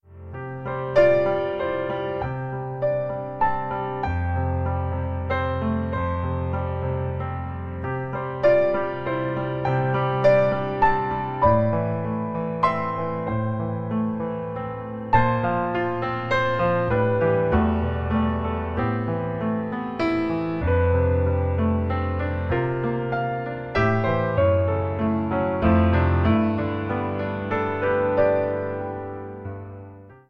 Perfekt zur Entspannung, Gebet und Lobpreis.
• Sachgebiet: Praise & Worship